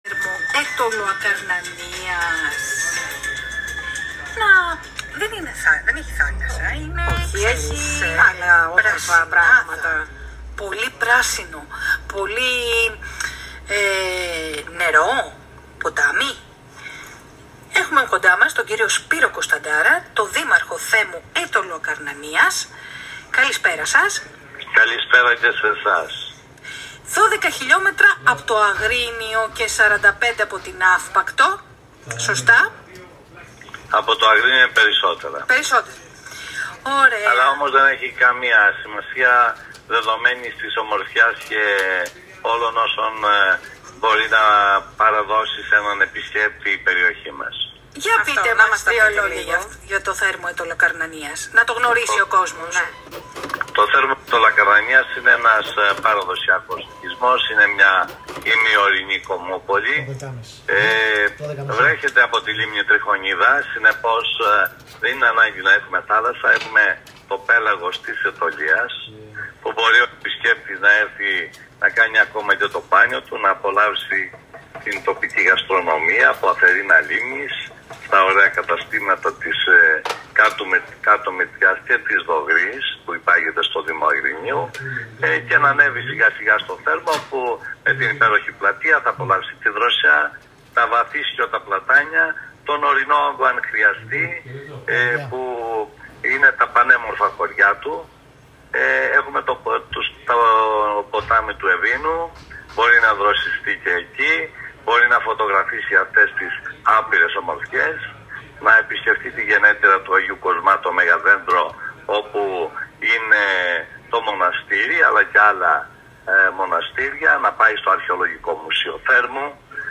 Σπύρος Κωνσταντάρας: Ο Δήμαρχος Θέρμου μιλά για τον τόπο του στο ραδιόφωνο της ΕΡΤ
Σε μια ιδιαίτερα ενδιαφέρουσα συνέντευξη στον ραδιοφωνικό σταθμό της ΕΡΤ, ο Δήμαρχος Θέρμου, κ. Σπύρος Κωνσταντάρας, μας ταξιδεύει στις ομορφιές του Θέρμου.